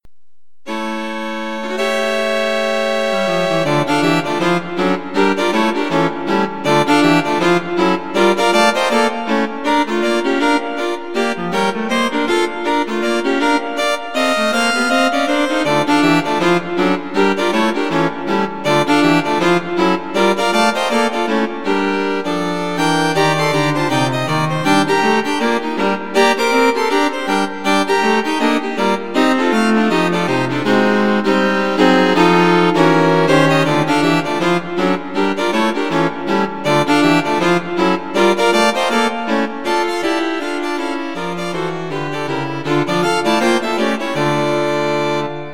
Wind Quartet for Concert performance
A short, lively piece for string quartet.